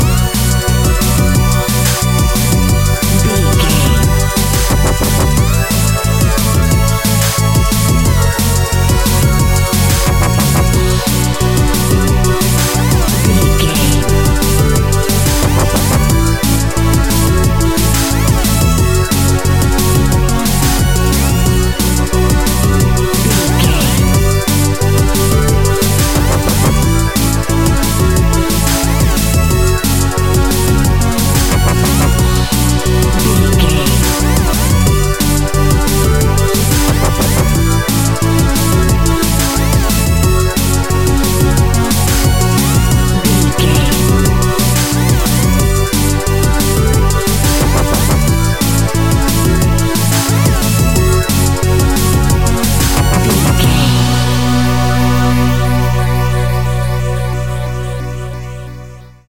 Epic / Action
Fast paced
Aeolian/Minor
aggressive
dark
driving
energetic
drum machine
synthesiser
sub bass
synth leads
synth bass